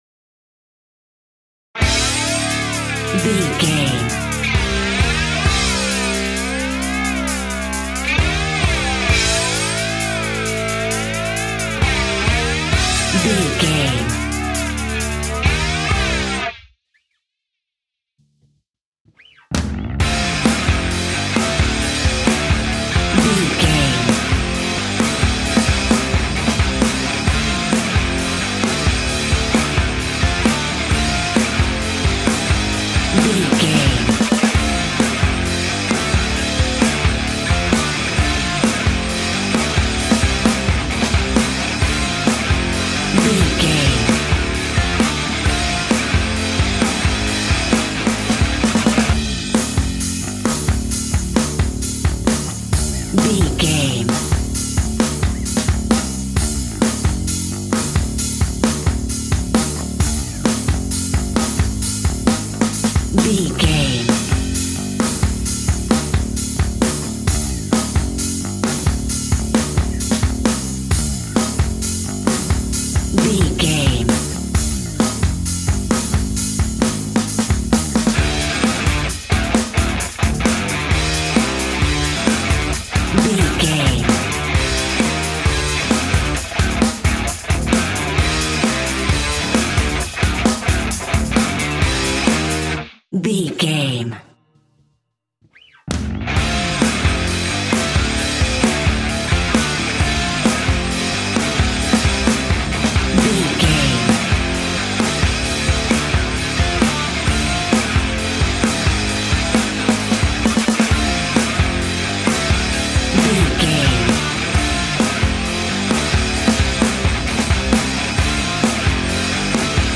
Epic / Action
Aeolian/Minor
hard rock
heavy metal
rock instrumentals
Heavy Metal Guitars
Metal Drums
Heavy Bass Guitars